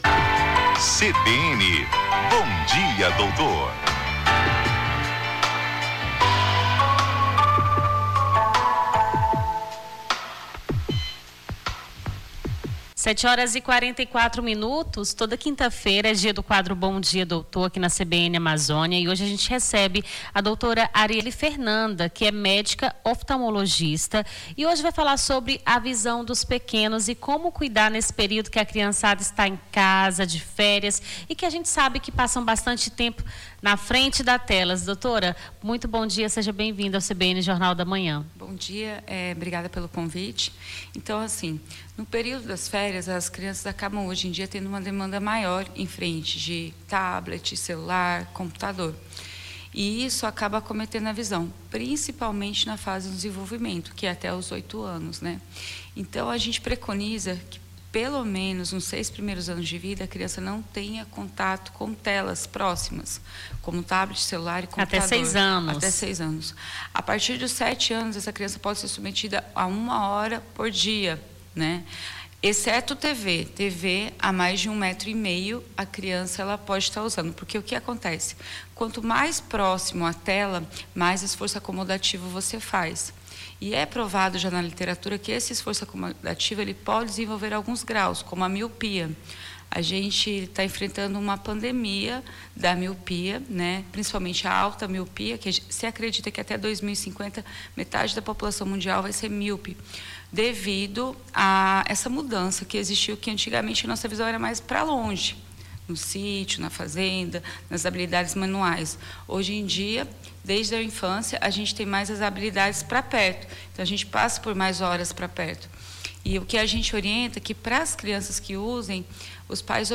QUADRO